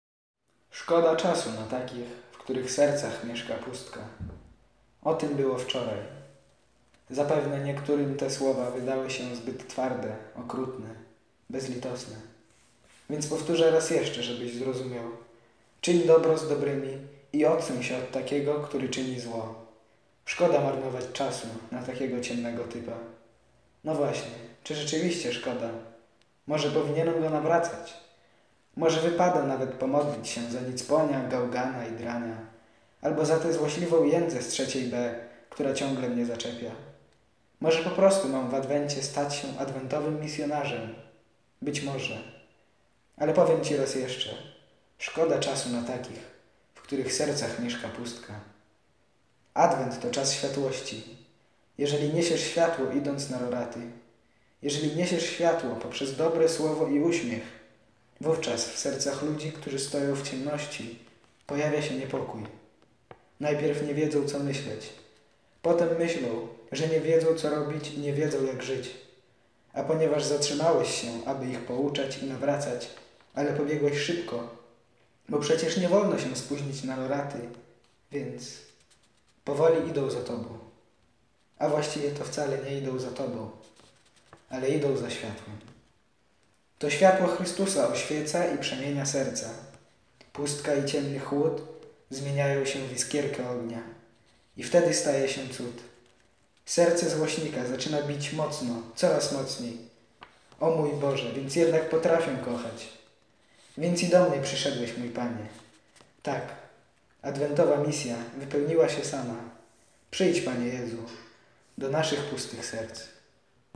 Komentarz do Ewangelii z dnia 28 listopada 2016